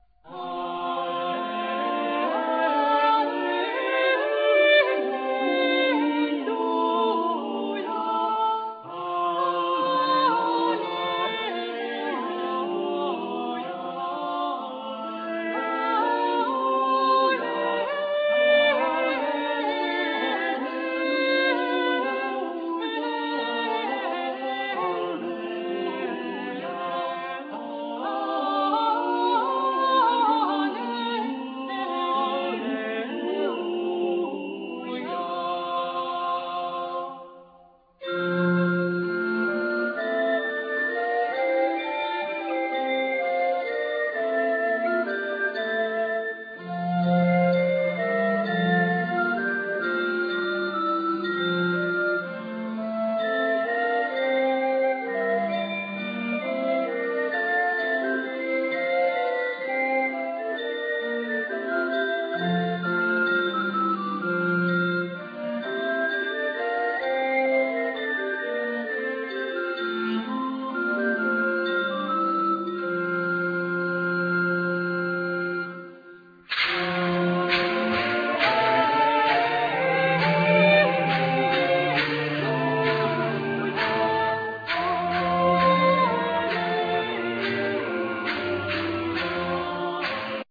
Flute,Recorder,Saxophone,Clarinets,Chalumeaux
Percussions
Soprano
Alto
Tenor
Bass
Viola da Gamba
Cembalo,Regal